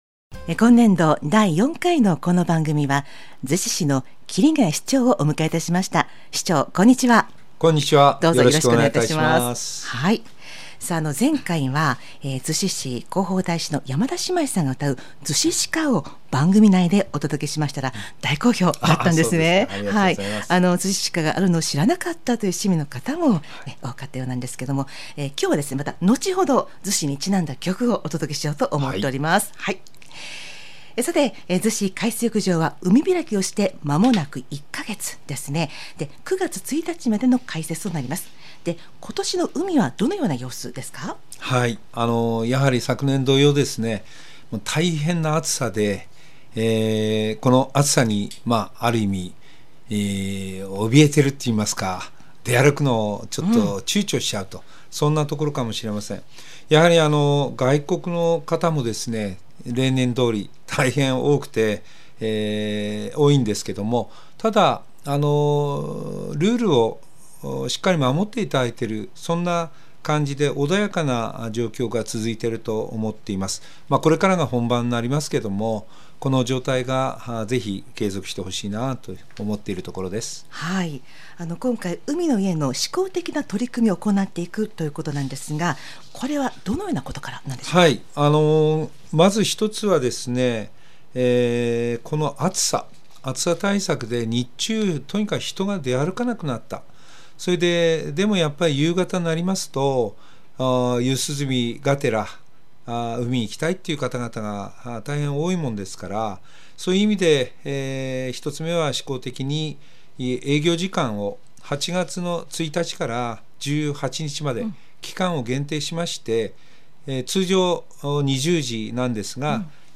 市町長県議インタビュー